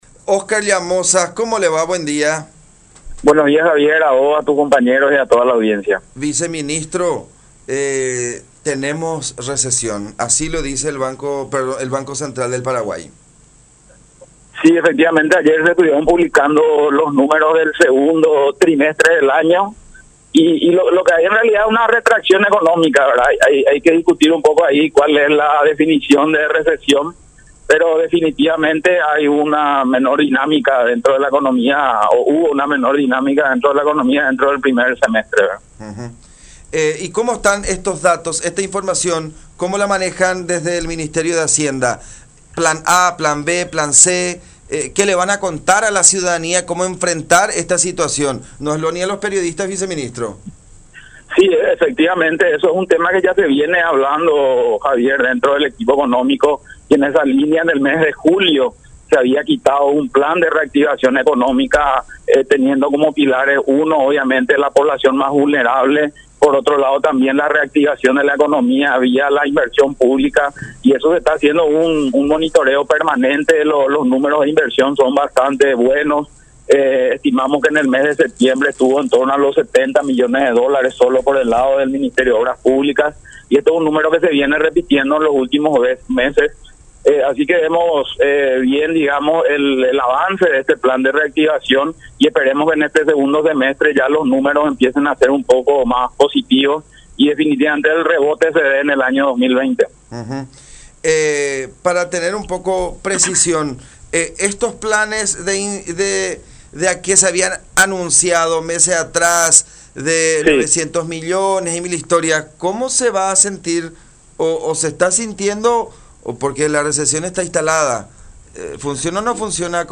Óscar Llamosas, viceministro de Hacienda, admitió que hubo una menor dinámica de la economía a lo largo del primer semestre del año, como lo informó el Banco Central del Paraguay (BCP).
“Esperemos que al finalizar este segundo semestre ya estemos hablando de números más positivos. Somos optimistas para que definitivamente el rebote se dé en el año 2020”, expresó en comunicación con La Unión.